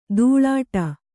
♪ dūḷāṭa